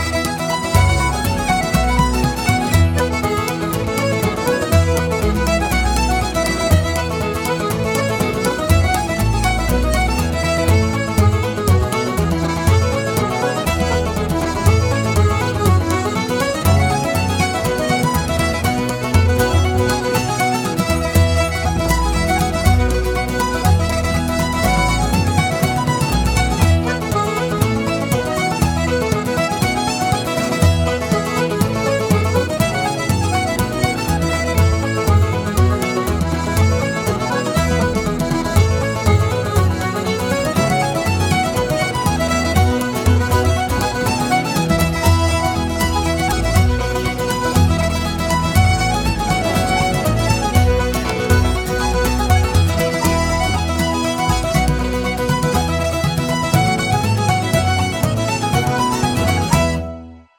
Bodrhan/Percussion
Guitars
Banjo/Mandolin
Piano/Piano Accordion